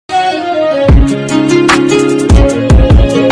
Mcdonalds_milkshake_but_sound.mp3